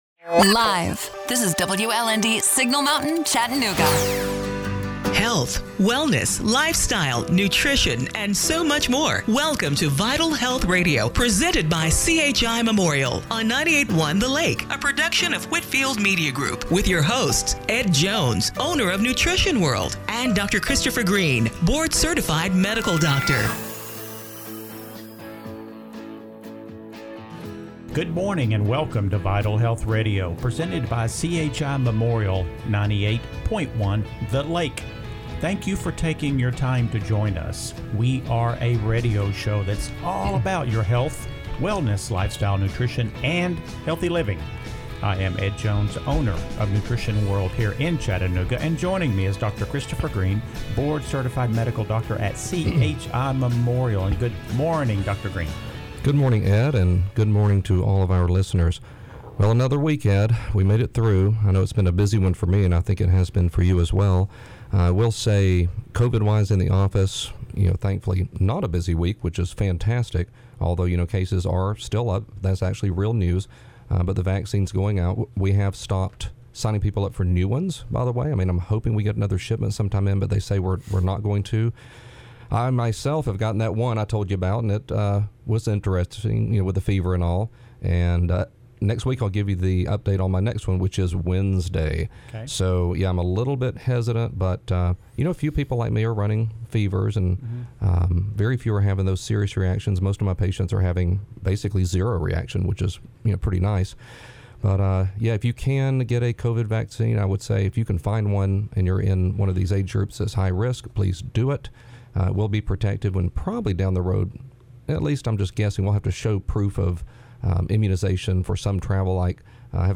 January 24, 2021 – Radio Show - Vital Health Radio